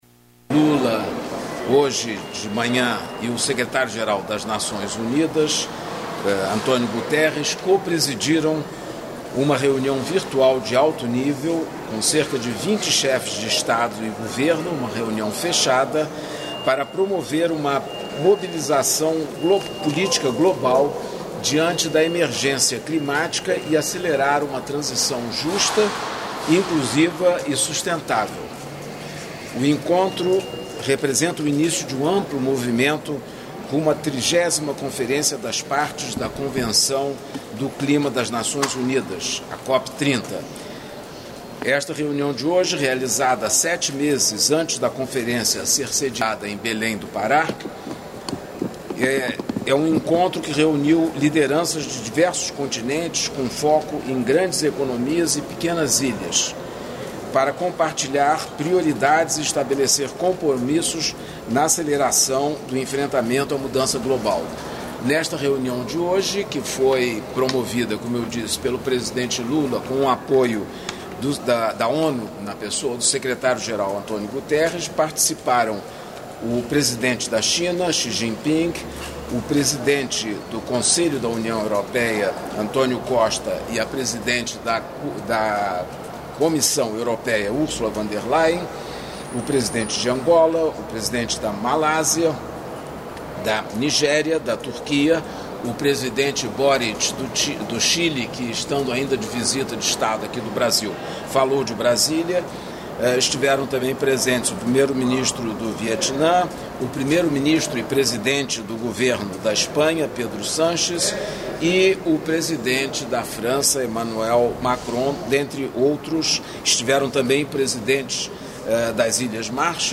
Íntegra da entrevista coletiva concedida pelos ministros da Justiça e Segurança Pública, Ricardo Lewandowski; da Controladoria-Geral da União, Vinicius de Carvalho; da Previdência Social, Carlos Lupi, e pelo diretor-geral da Polícia Federal, Andrei Rodrigues, sobre a Operação Sem Desconto, no auditório do Ministério da Justiça, em Brasília (DF).